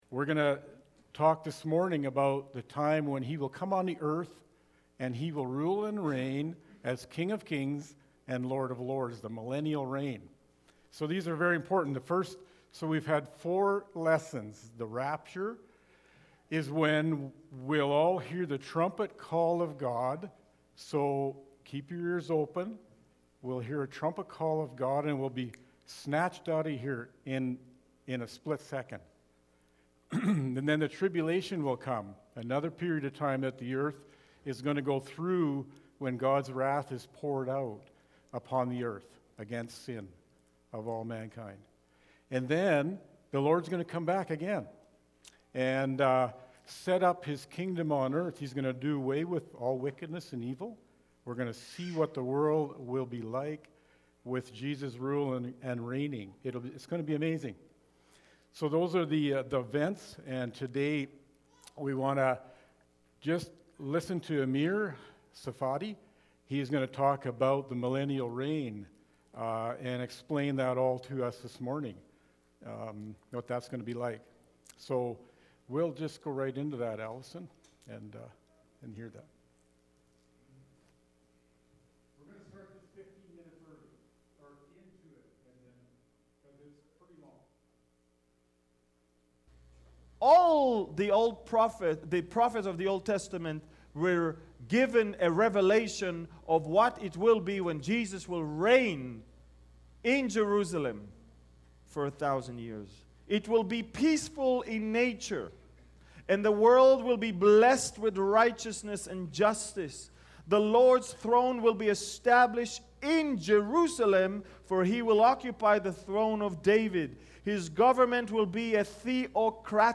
Nov-1-2020-sermon.mp3